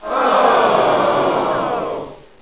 MOAN1.mp3